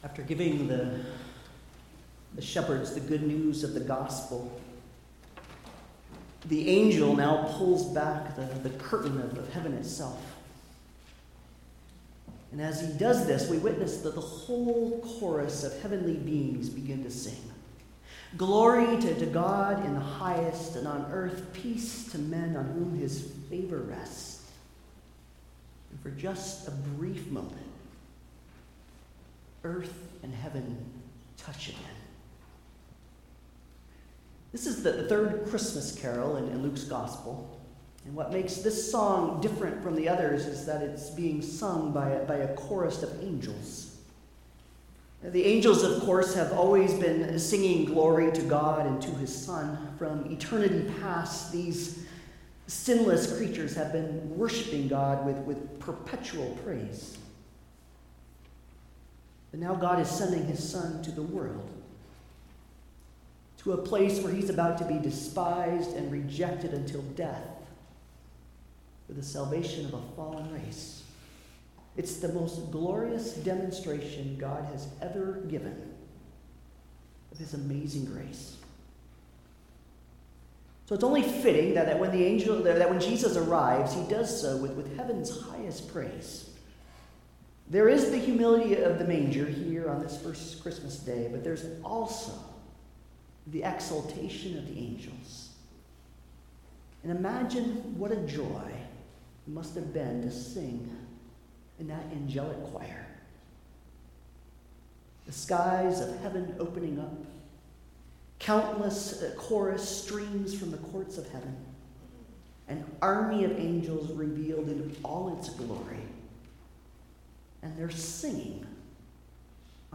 Passage: Luke 2:8-20 Service Type: Holiday Service